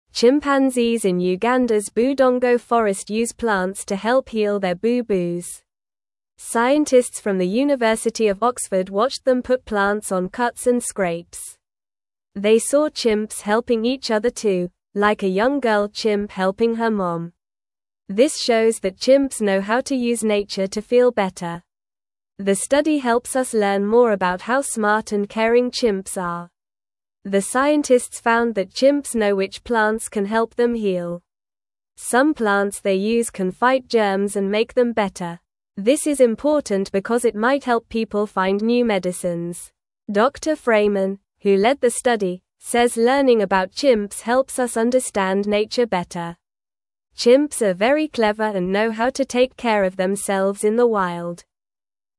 Normal
English-Newsroom-Beginner-NORMAL-Reading-Chimps-Use-Plants-to-Heal-Their-Boo-Boos.mp3